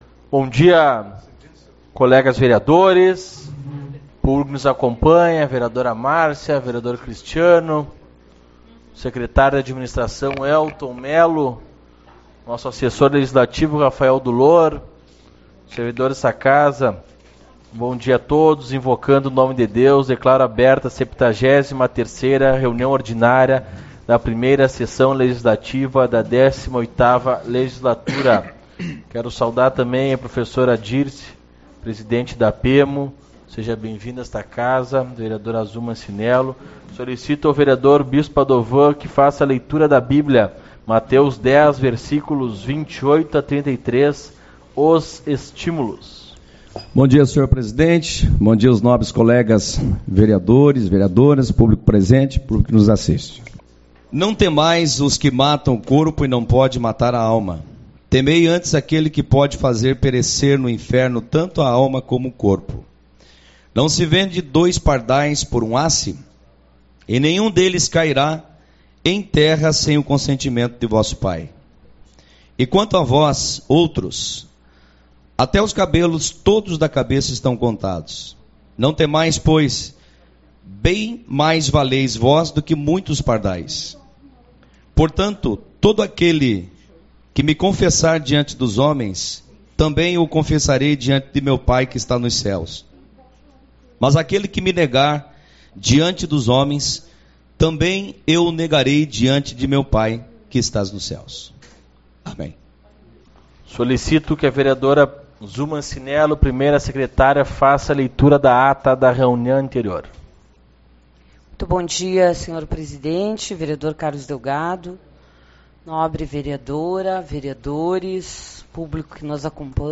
09/11 - Reunião Ordinária